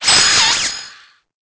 Cri_0872_EB.ogg